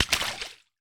water_splash_small_item_02.wav